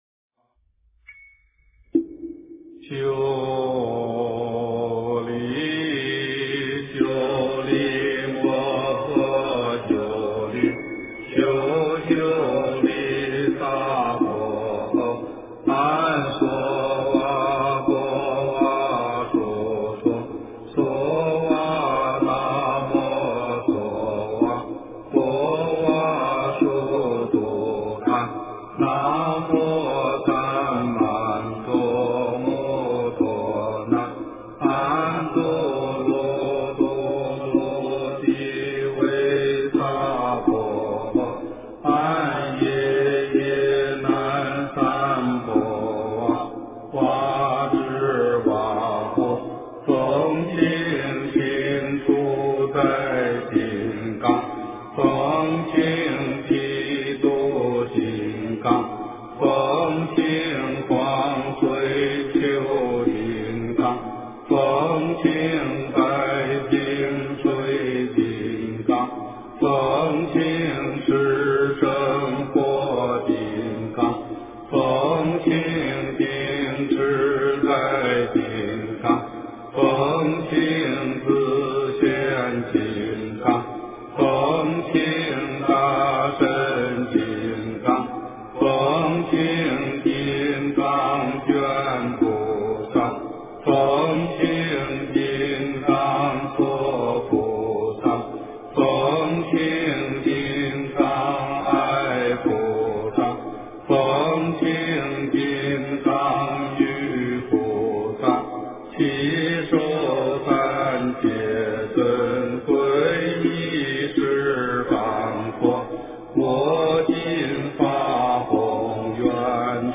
金刚般若波罗蜜经 - 诵经 - 云佛论坛
佛音 诵经 佛教音乐 返回列表 上一篇： 心经(念诵